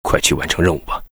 文件 文件历史 文件用途 全域文件用途 Bk2_fw_05.ogg （Ogg Vorbis声音文件，长度1.1秒，126 kbps，文件大小：17 KB） 源地址:游戏语音 文件历史 点击某个日期/时间查看对应时刻的文件。